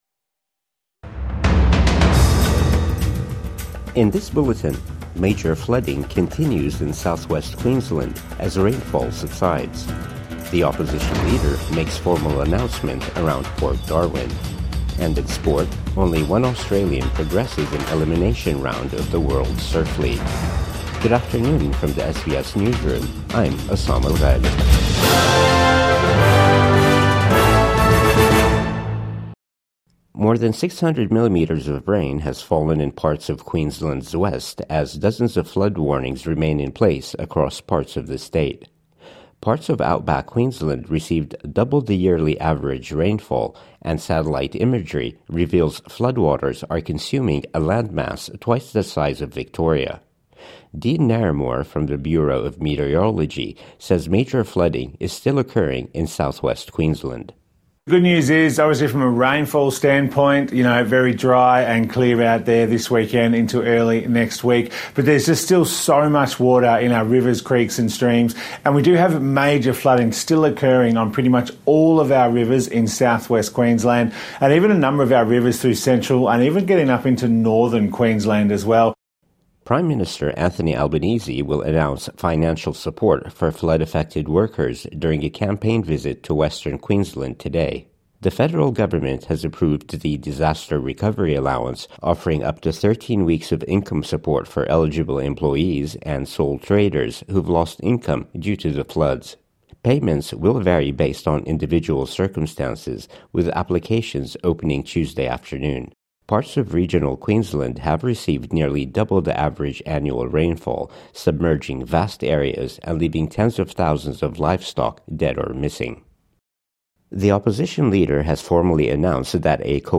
Midday News Bulletin 5 April 2025